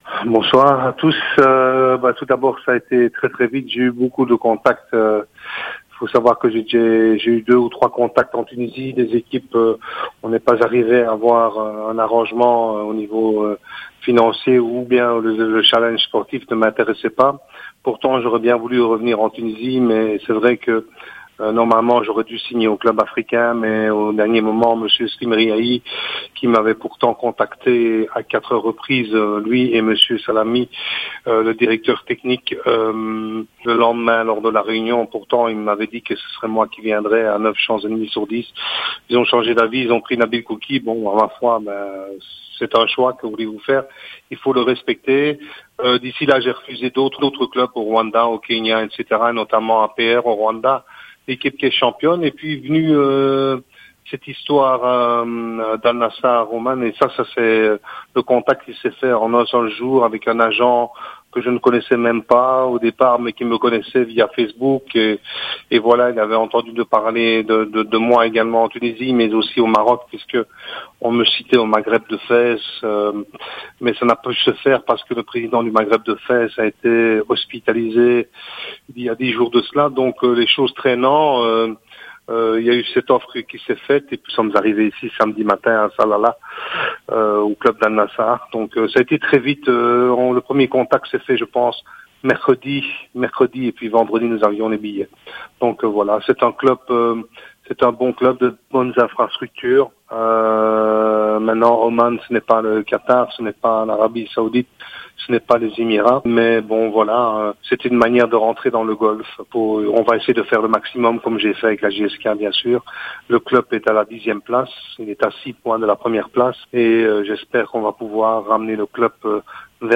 حوار حصري لراديو جوهرة